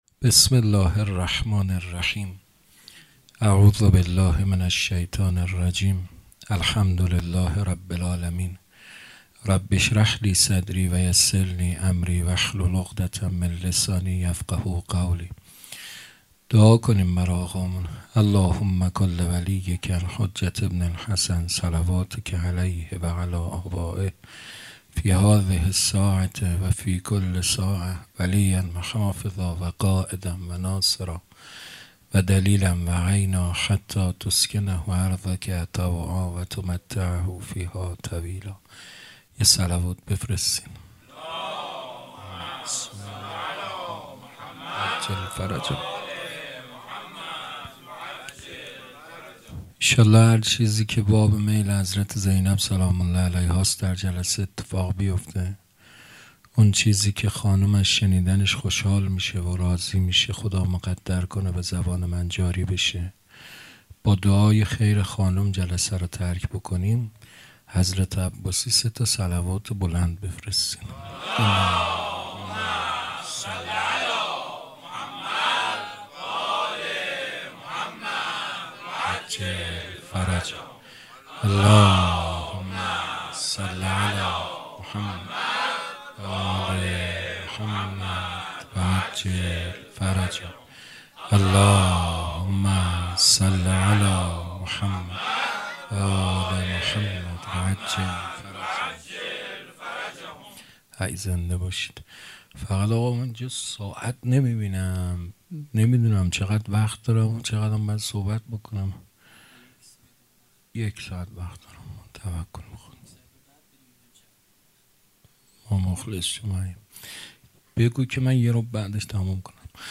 سخنرانی شب اول